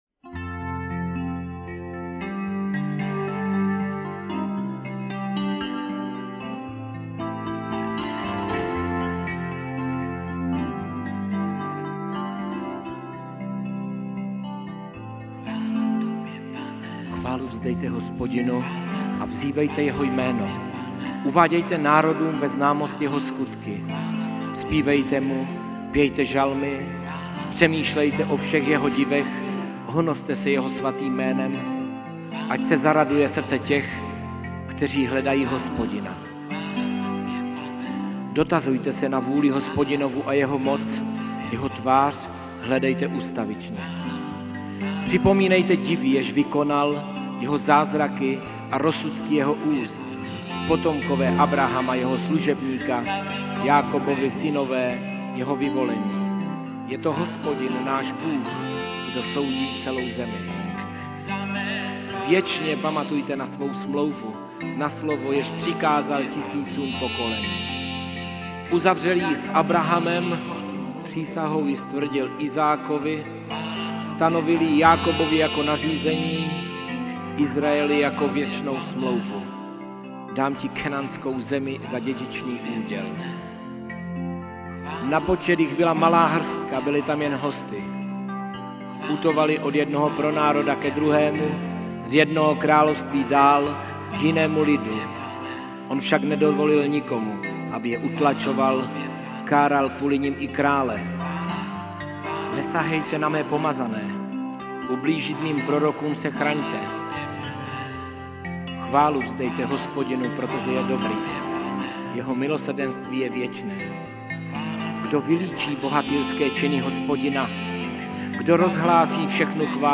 Písně ke chvále a uctívání